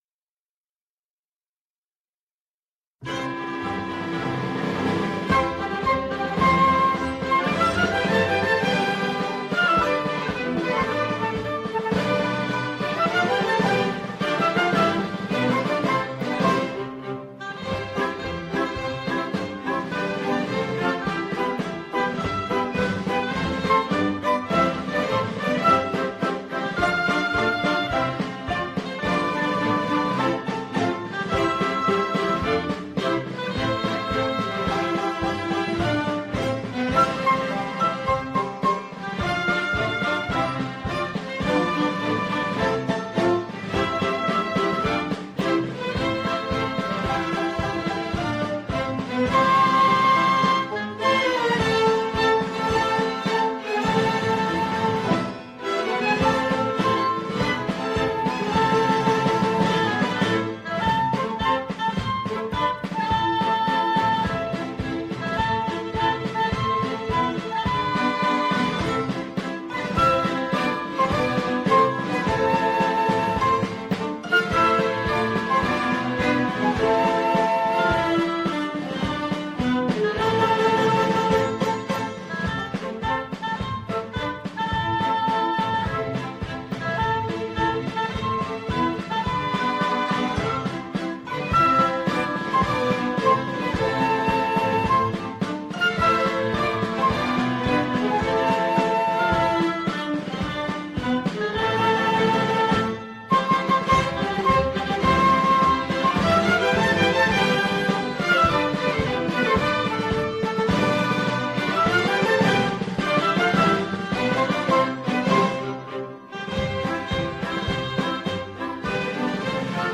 سرودهای دهه فجر
بی‌کلام